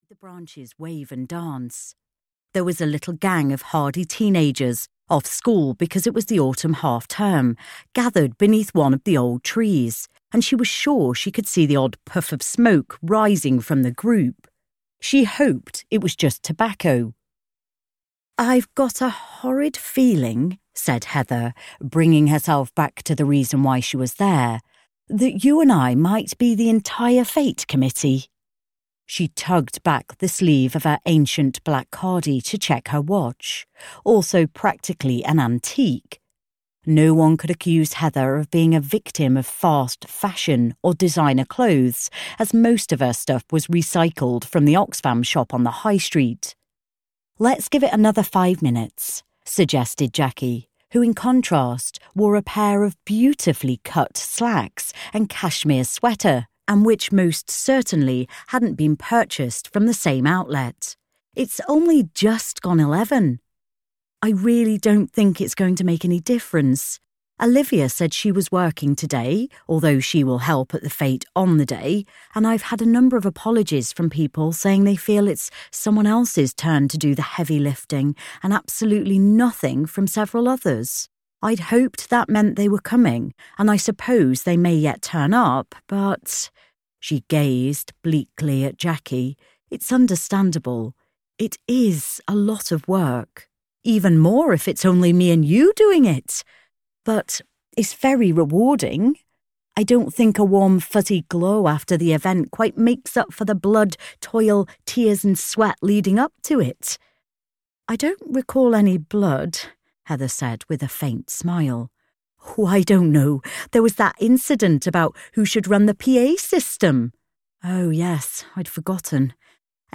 Fates and Fortunes in Little Woodford (EN) audiokniha
Ukázka z knihy